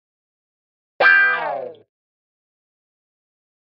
Guitar Thin Wah-Wah Finale Chord 1